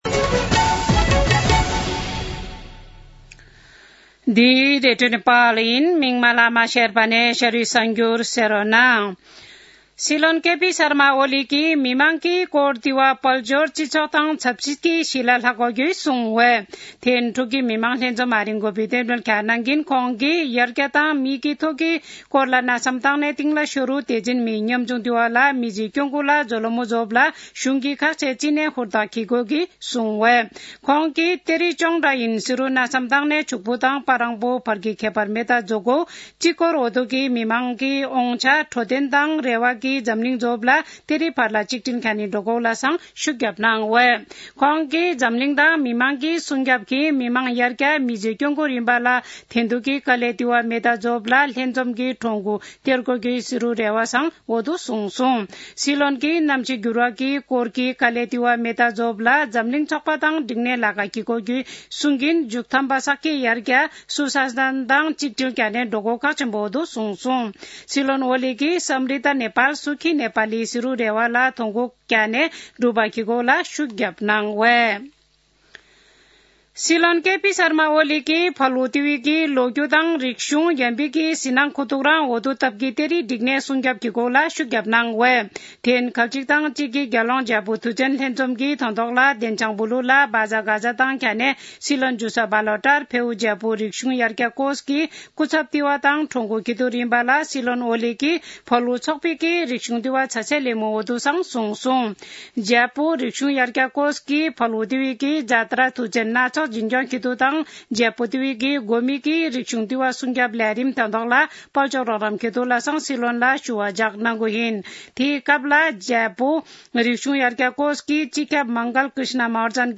शेर्पा भाषाको समाचार : १३ मंसिर , २०८१
4-pm-Sherpa-news-1-5.mp3